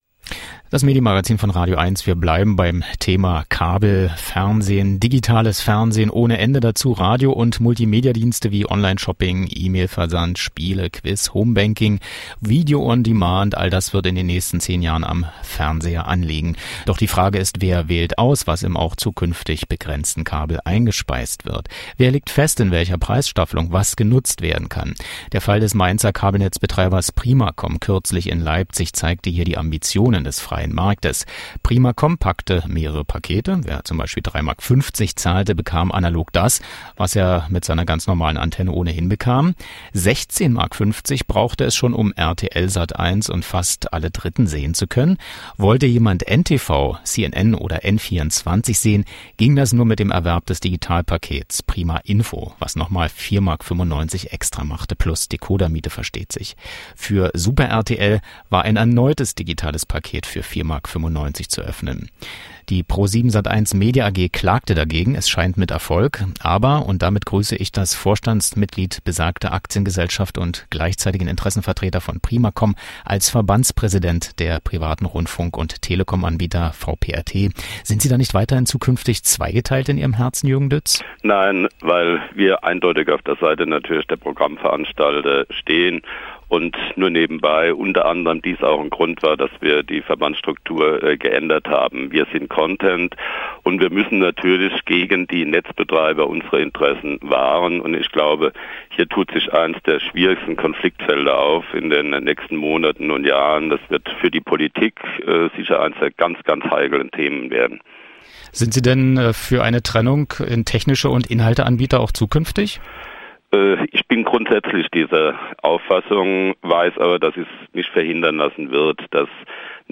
Telefoninterview